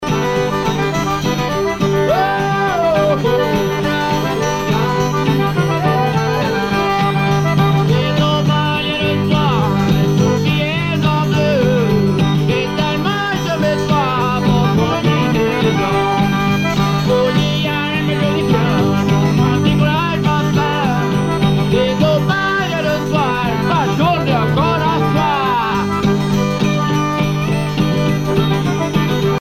Cajun
Pièce musicale éditée